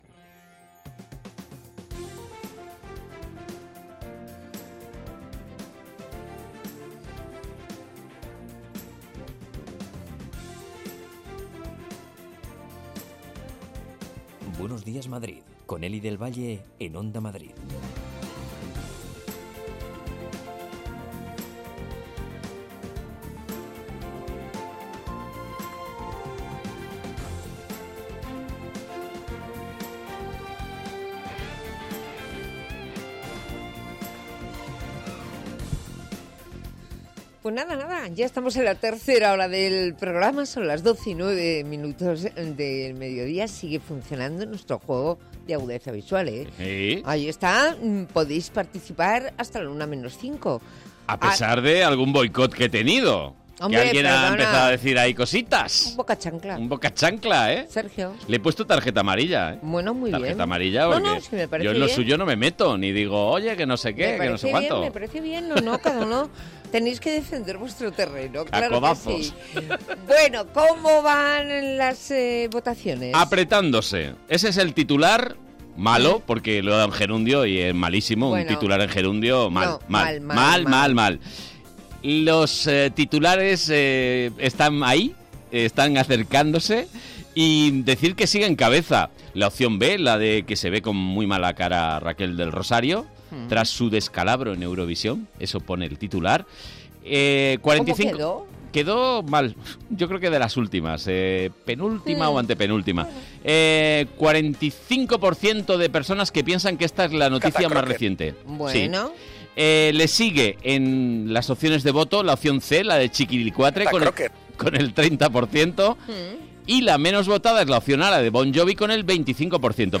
Tres horas más de radio donde se habla de psicología, ciencia, cultura, gastronomía, medio ambiente, y consumo.